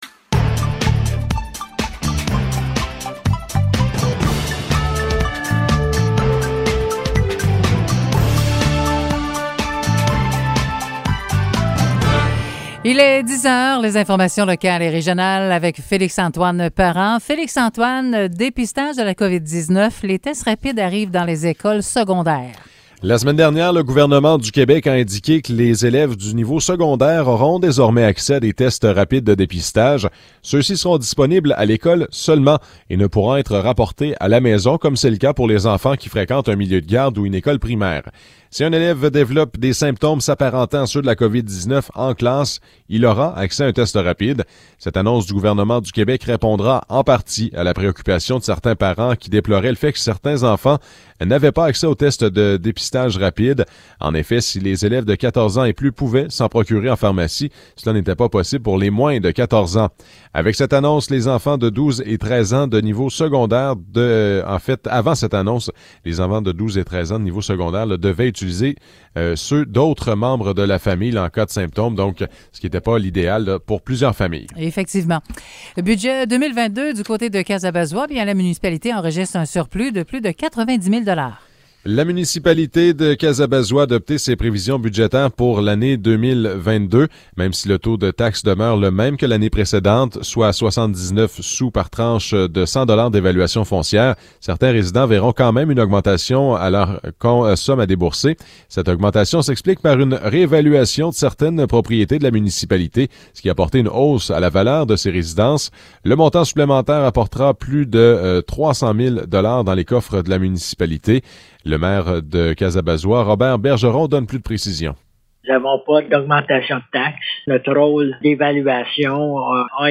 Nouvelles locales - 24 janvier 2022 - 10 h